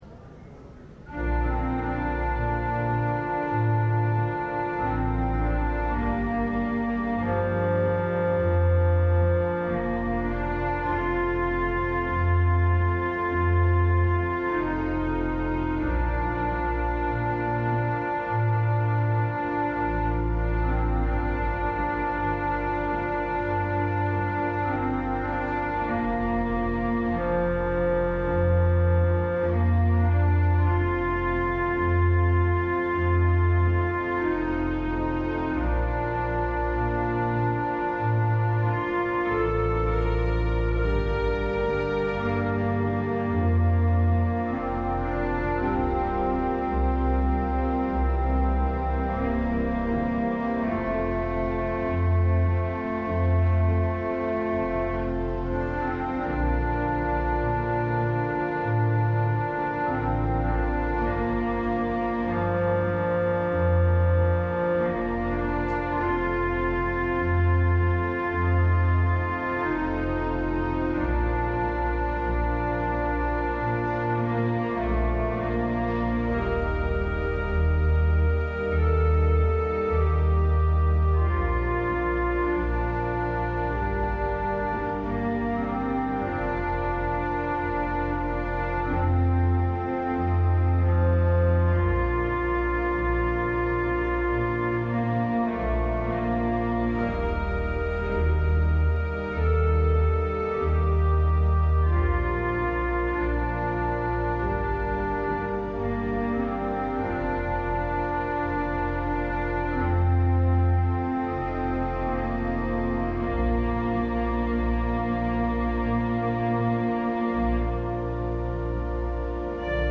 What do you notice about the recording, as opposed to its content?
LIVE Evening Worship Service - Healing and Forgiveness